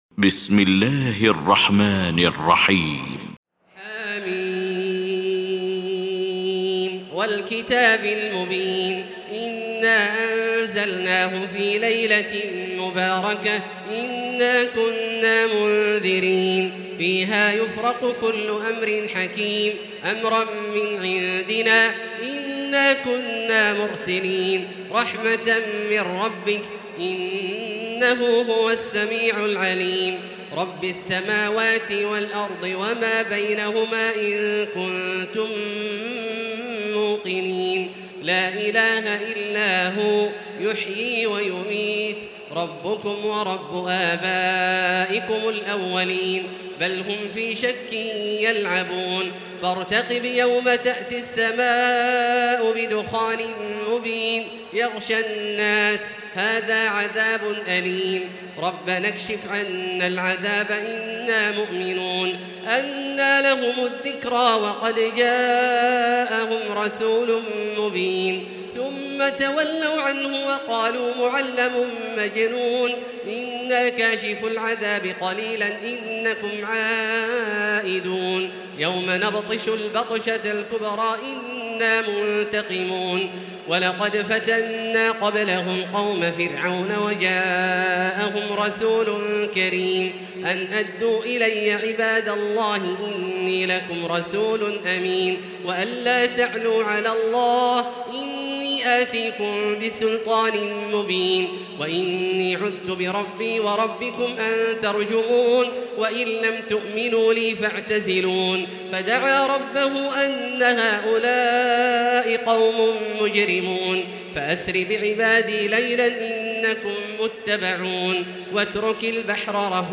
المكان: المسجد الحرام الشيخ: فضيلة الشيخ عبدالله الجهني فضيلة الشيخ عبدالله الجهني الدخان